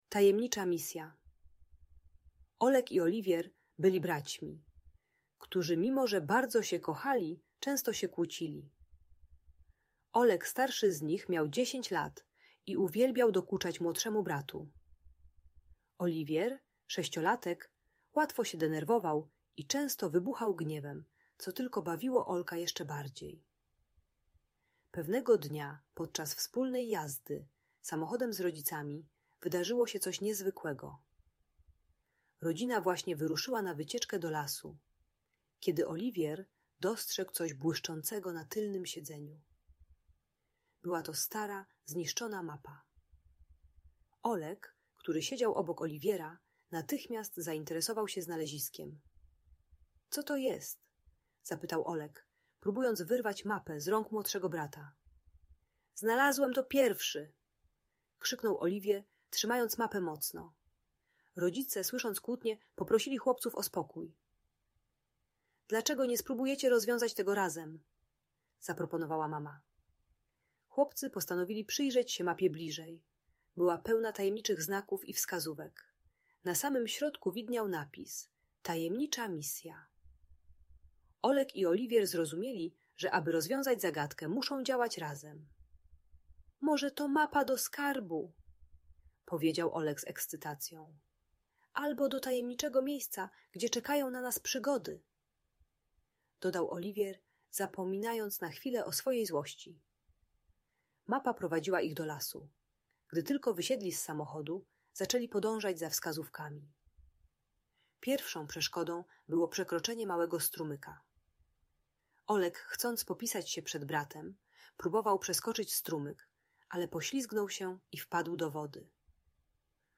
Tajemnicza Misja - Audiobajka